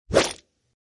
SwordfisherThrow.ogg